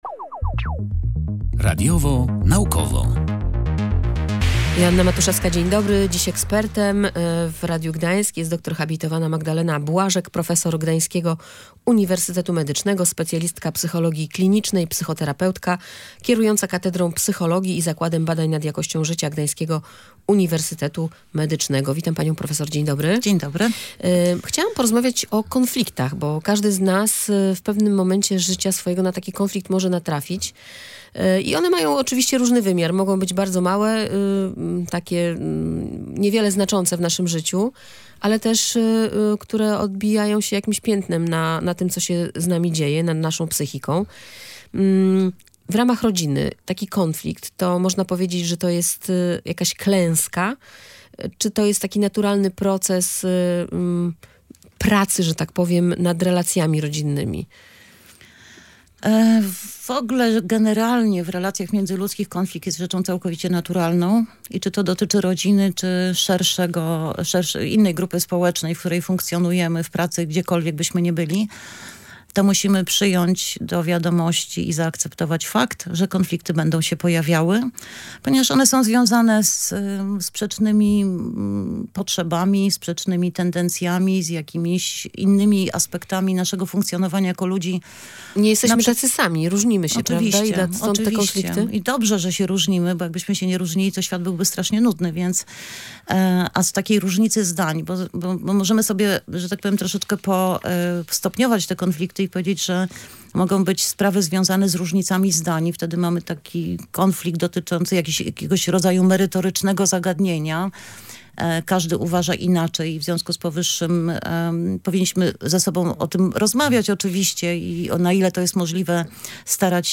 Natura konfliktów w relacjach międzyludzkich. Rozmowa ze specjalistką psychologii klinicznej